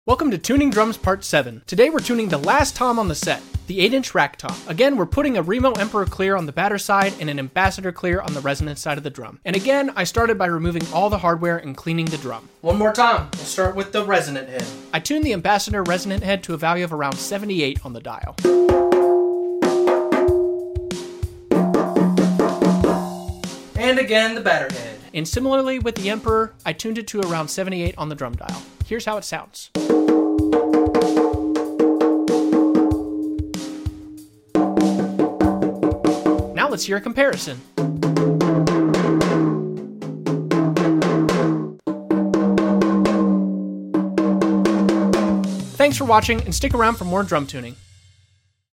Tuning my 8" Rack Tom sound effects free download